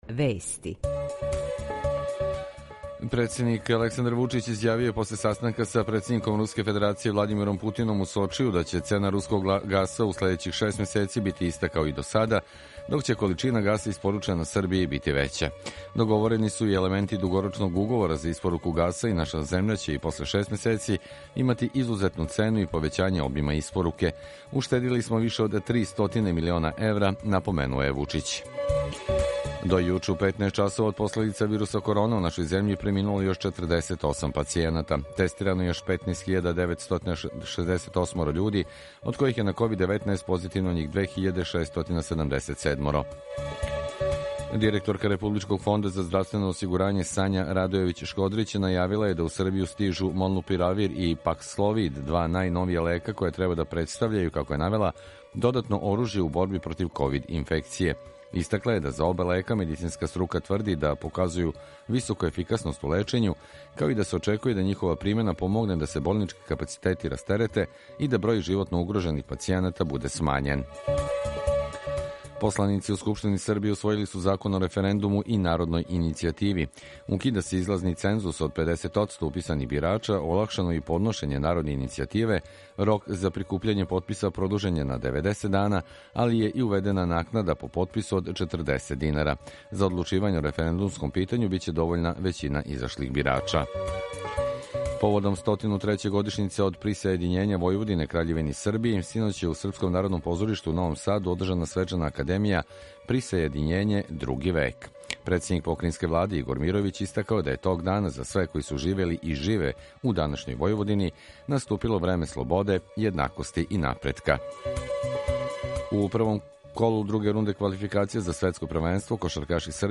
Емисију реализујемо заједно са студијом Радија Републике Српске у Бањалуци и Радија Новог Сада
У два сата, ту је и добра музика, другачија у односу на остале радио-станице.